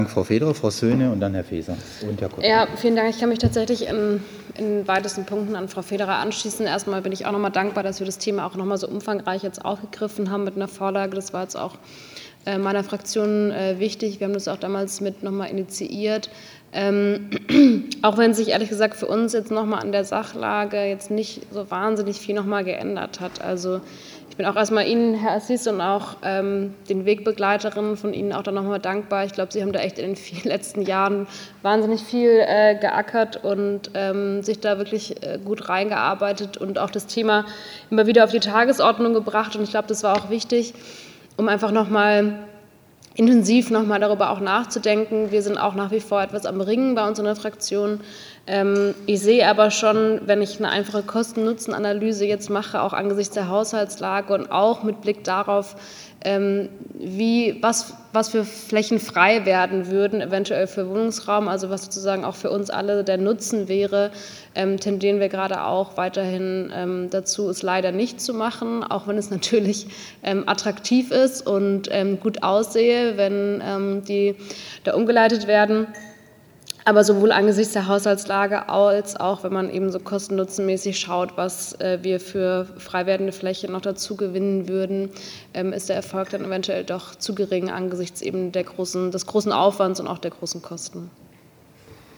5. BaUStA Ausschuss in Freiburg: Leben unter Hochspannungsleitungen soll bleiben - Verlegung und Vergrabung für Stadt zu teuer